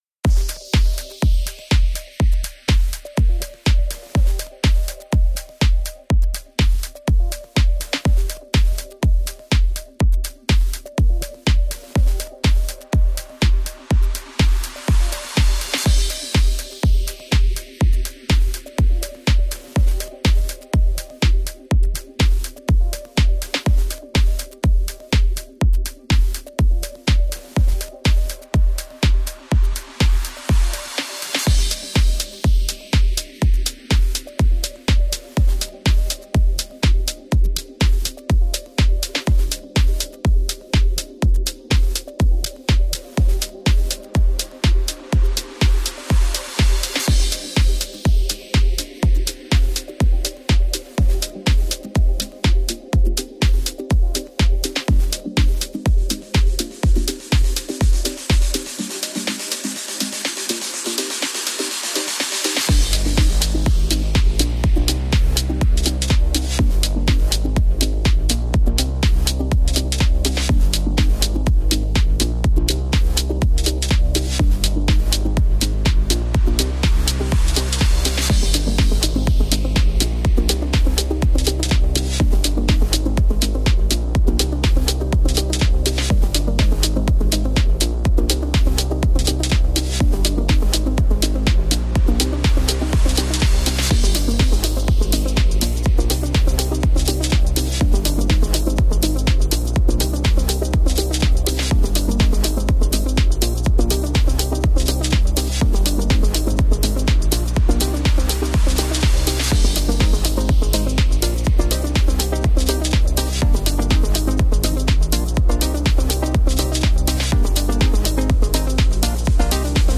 Genre: Melodic House